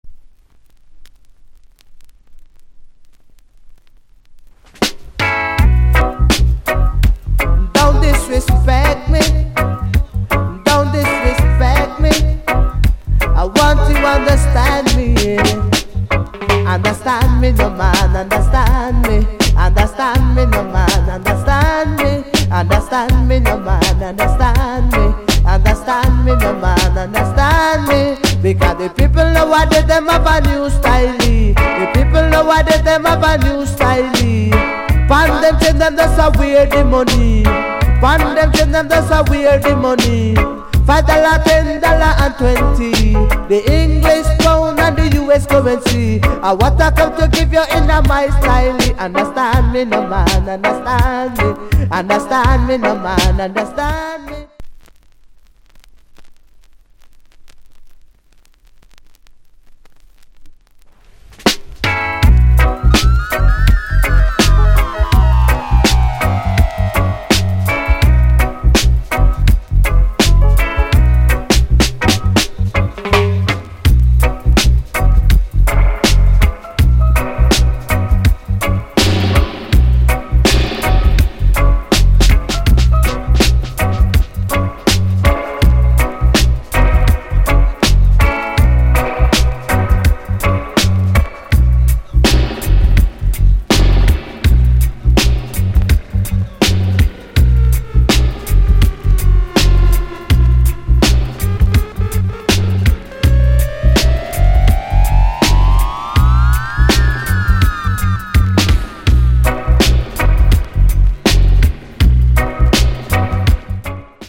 Rub A Dub Vocal Tune